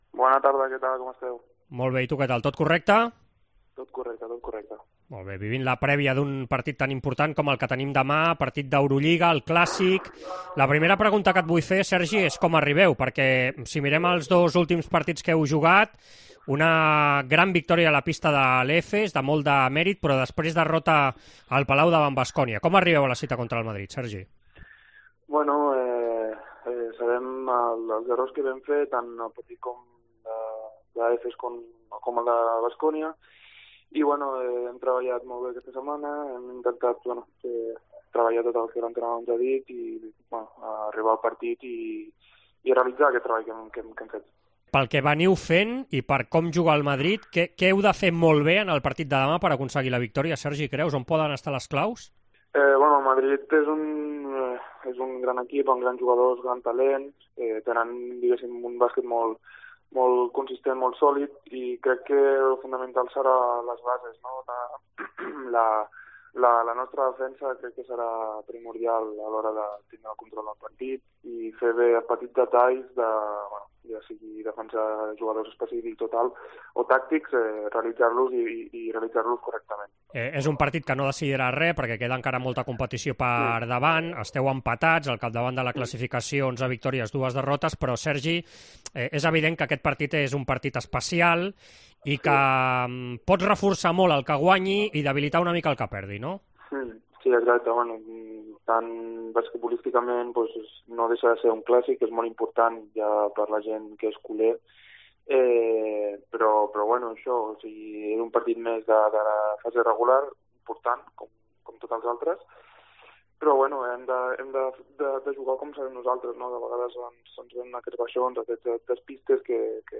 El jugador del FC Barcelona analitza el moment de l'equip de Sarunas Jasikevicius abans del partit d'Eurolliga contra el Real Madrid aquest divendres i l'arribada de Dante Exum.